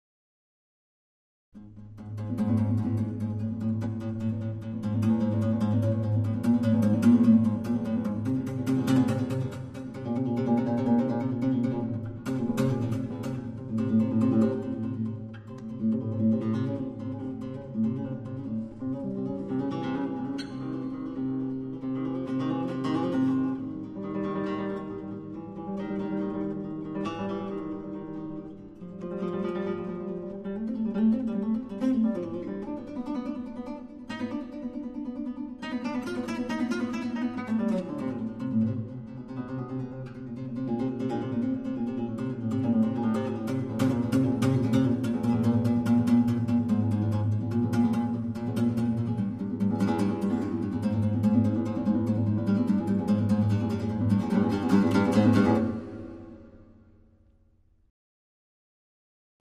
* Live Performances